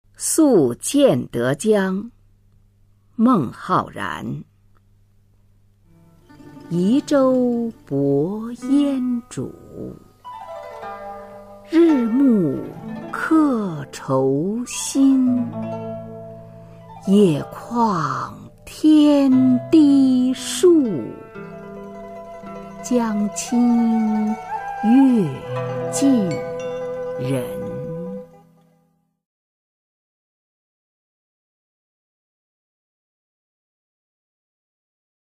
[隋唐诗词诵读]孟浩然-宿建德江（女） 配乐诗朗诵